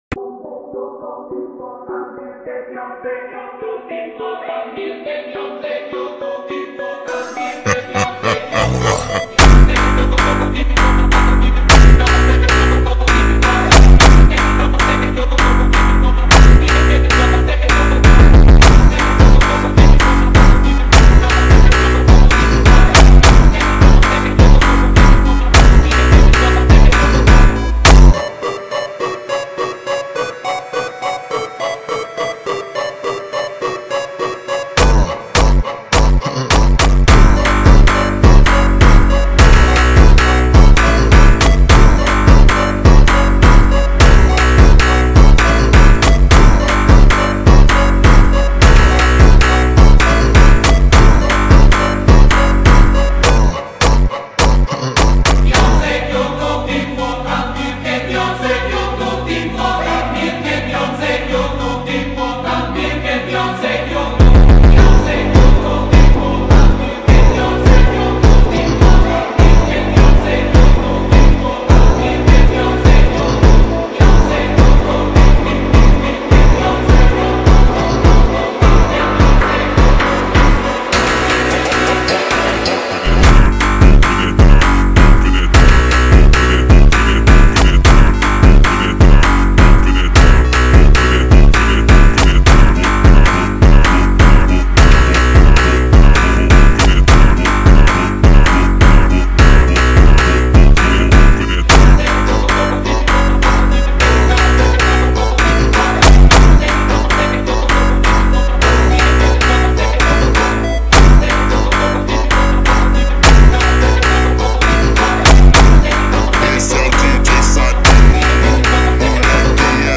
فانک پر قدرت
در ورژن Slowed و کند شده
فانک